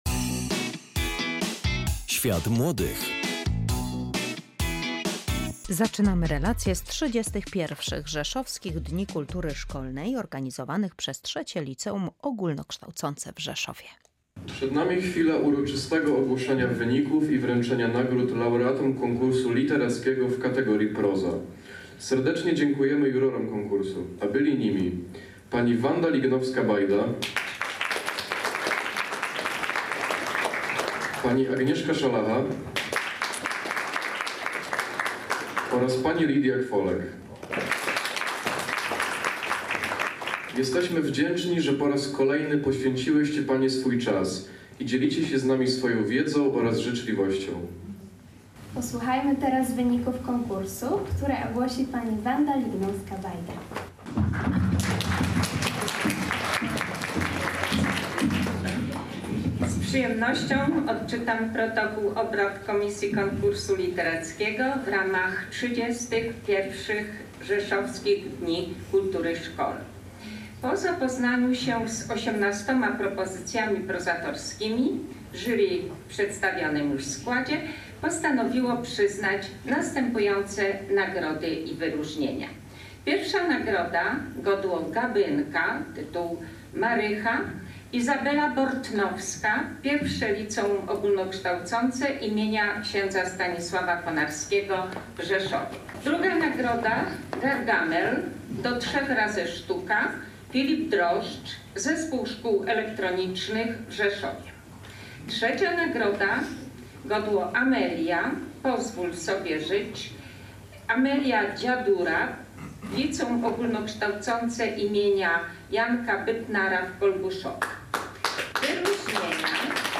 Zapraszamy do wysłuchania relacji z XXXI Rzeszowskich Dni Kultury Szkolnej organizowanych od samego początku przez III Liceum Ogólnokształcące w Rzeszowie.